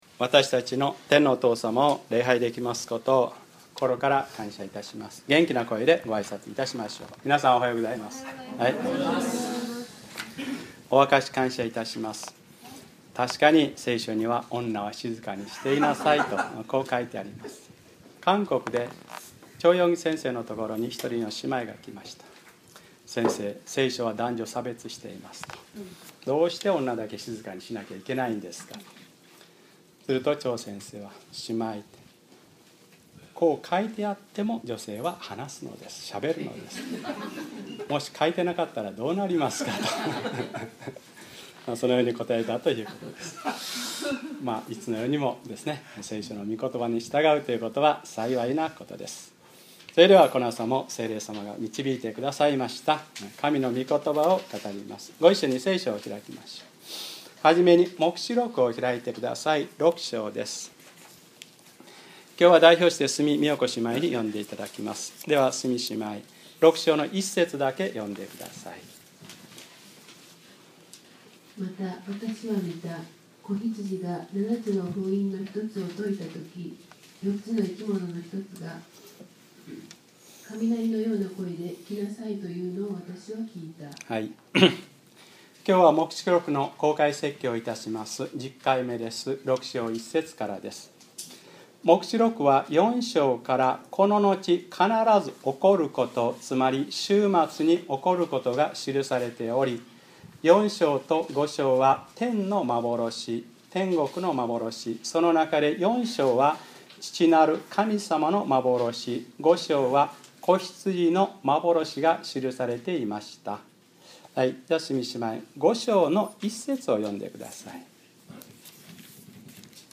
2012年7月22日(日）礼拝説教 『黙示録10/ 6章1節より』